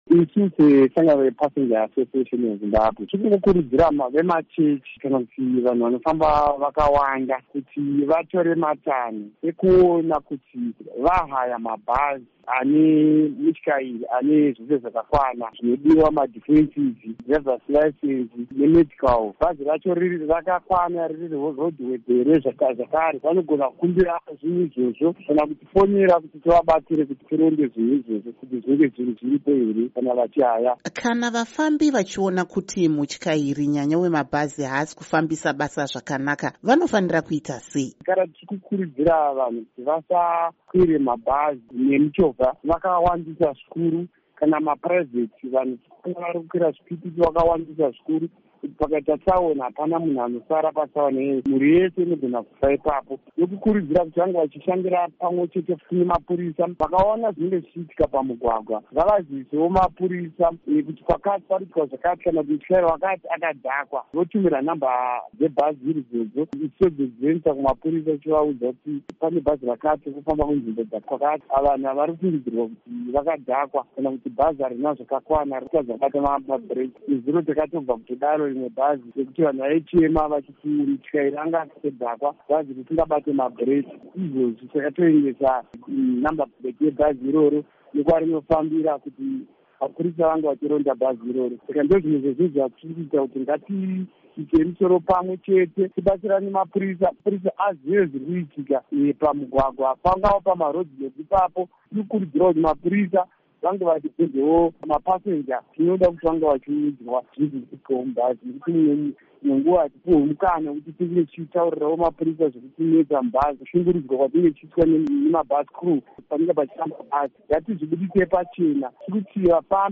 Hurukuro